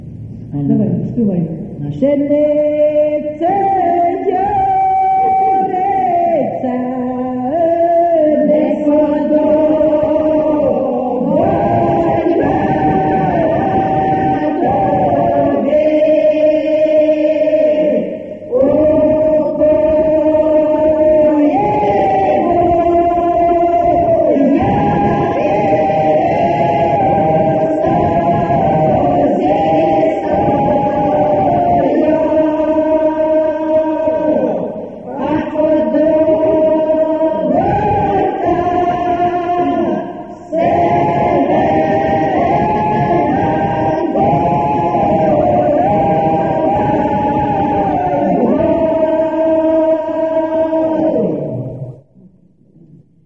ЖанрВесільні